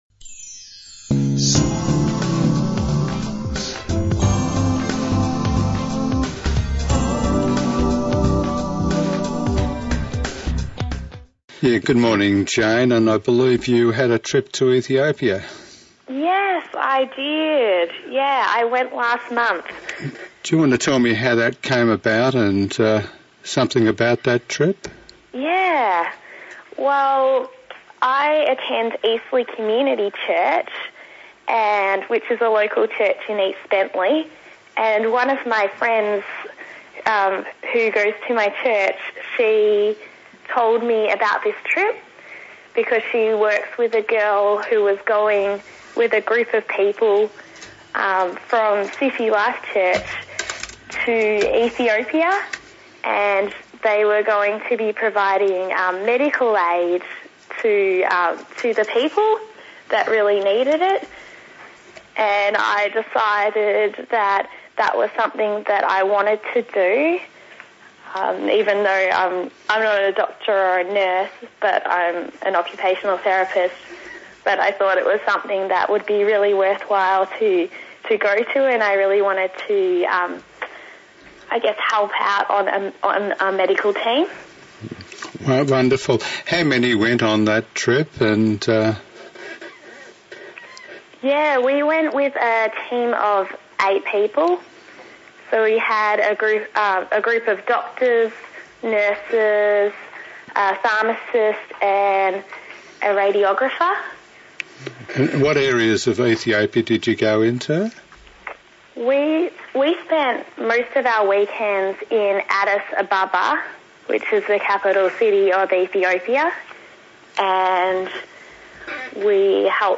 Songs of Hope interview